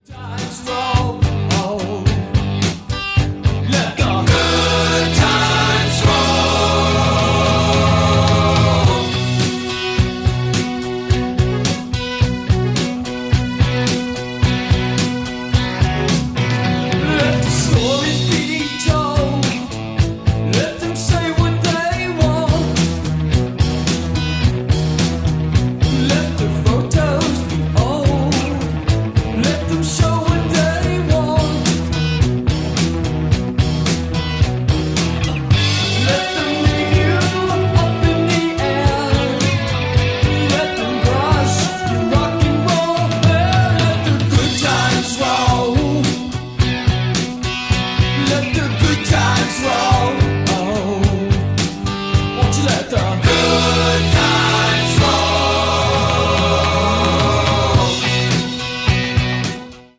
Last Drop Coffee Shop Car Sound Effects Free Download